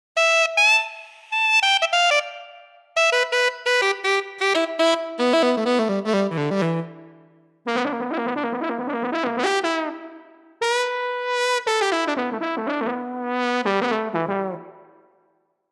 Эти фрагменты были записаны в Wav-файл на звуковой карте SW1000XG и конвертированы в mp3 с помощью программы CDex Version 1.30.
Соло альт саксофона, сменяющееся тромбоном.
PLG100-VL исполняет партию саксофона отчетливей.